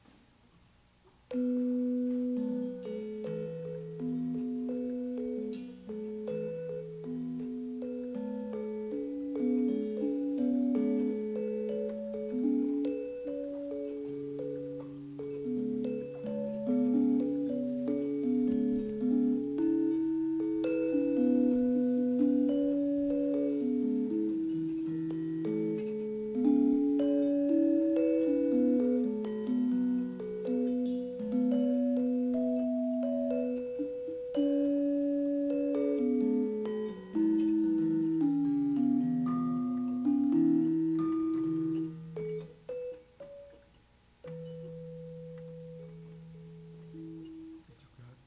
The court-style gendèr barung transcription is based on the recording: